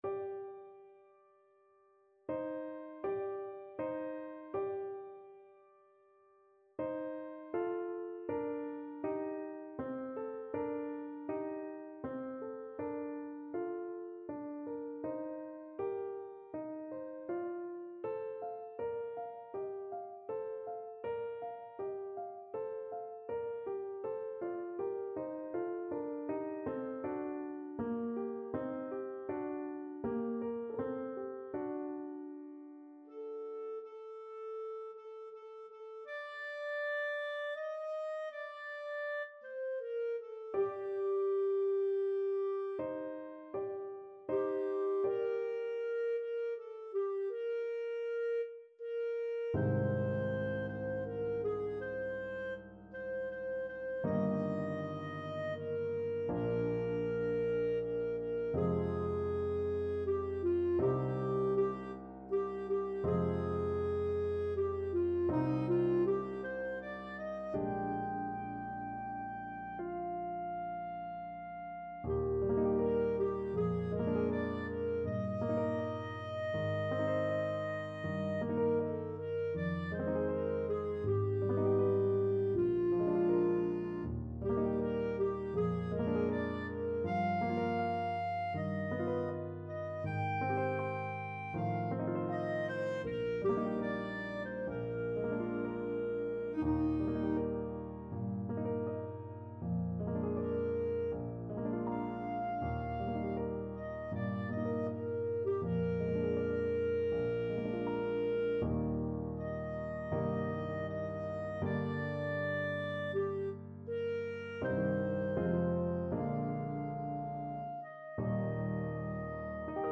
Clarinet
3/4 (View more 3/4 Music)
G minor (Sounding Pitch) A minor (Clarinet in Bb) (View more G minor Music for Clarinet )
Andantino = c.80 (View more music marked Andantino)
Classical (View more Classical Clarinet Music)
jocelyn_berceuse_CL.mp3